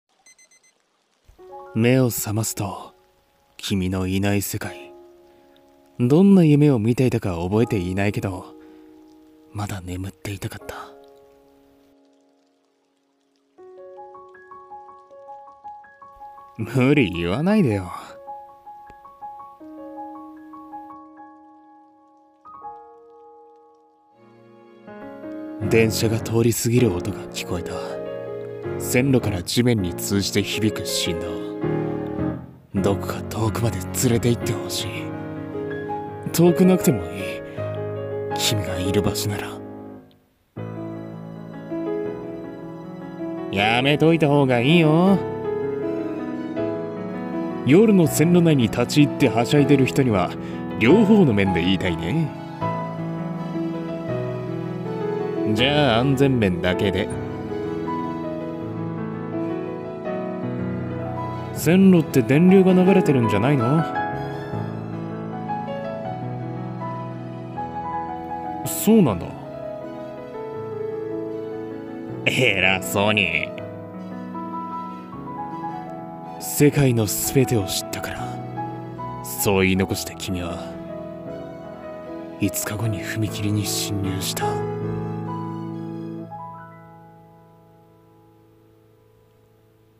耳に残る、響き。【二人声劇】 演◆